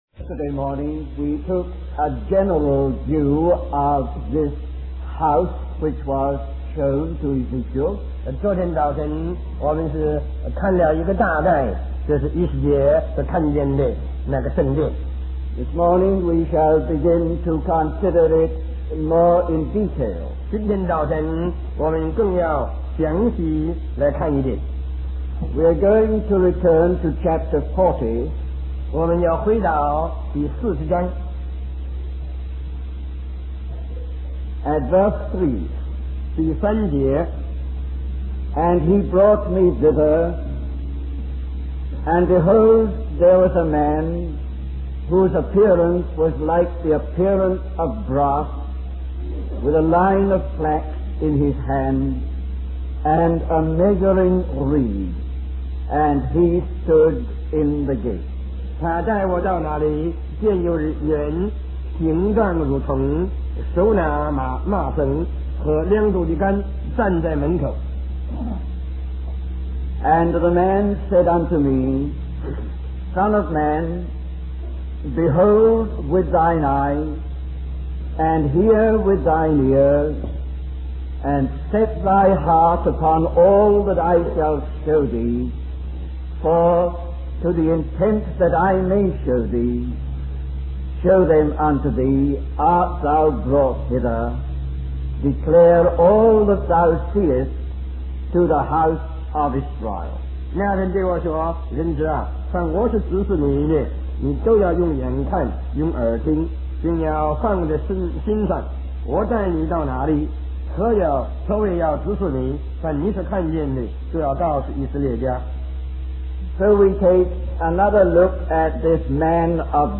A collection of Christ focused messages published by the Christian Testimony Ministry in Richmond, VA.
Taiwan, Republic Of China